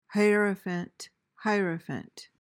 PRONUNCIATION:
(HY-uhr-uh-fant, HY-ruh-)